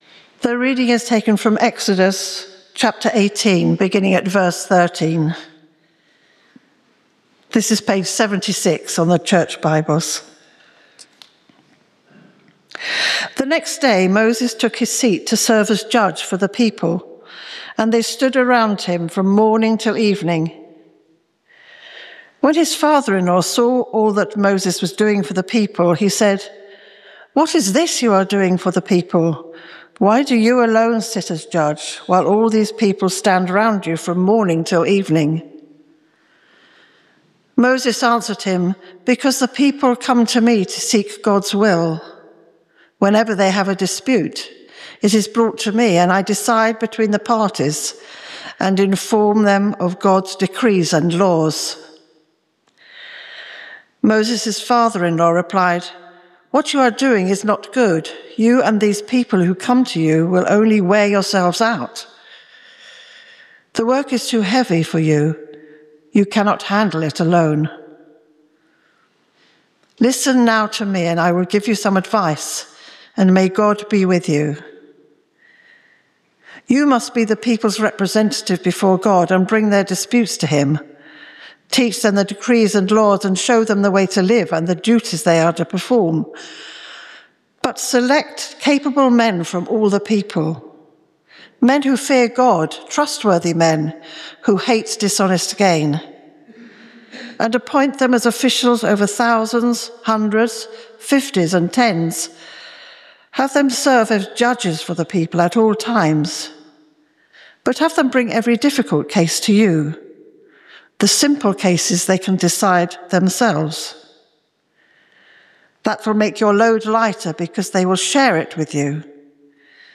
St Mary’s, Slaugham – Informal Worship Speaker